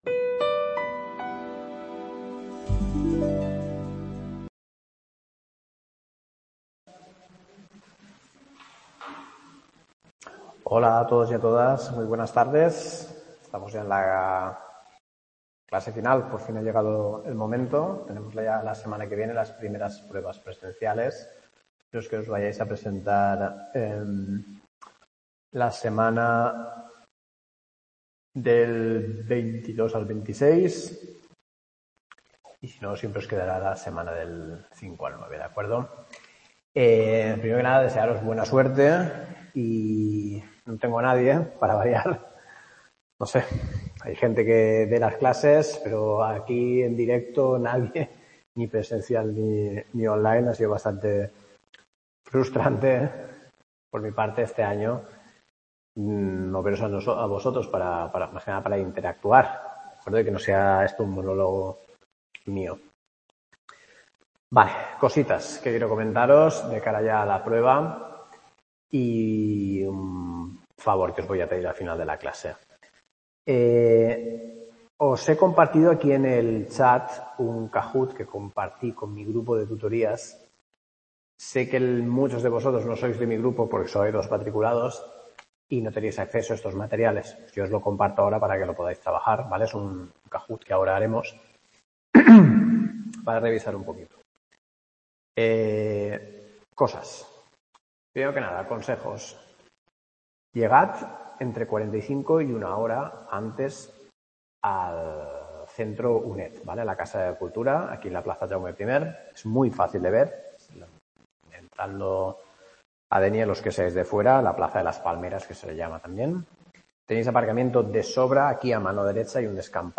CLASE 12 +1 Teoría literaria | Repositorio Digital